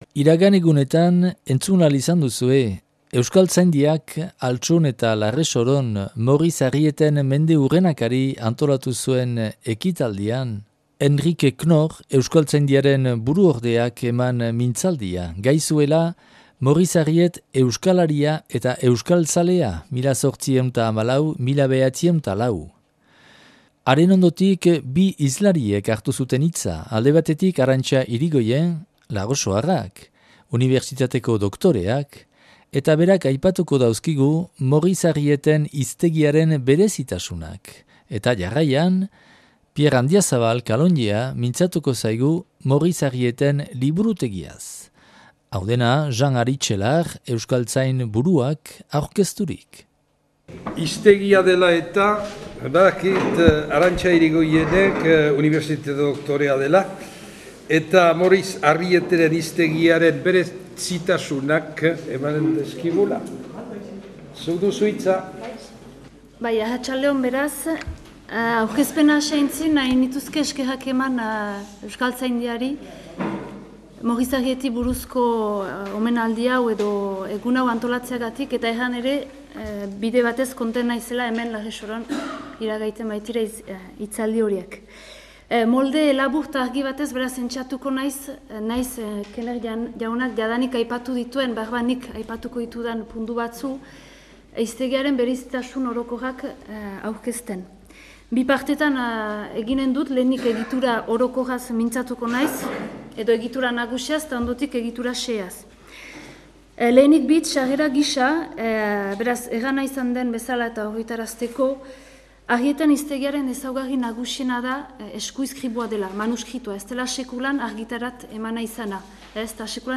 (Larresoron grabatua 2004. ekainaren 4an).